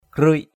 /krɯɪʔ/ 1.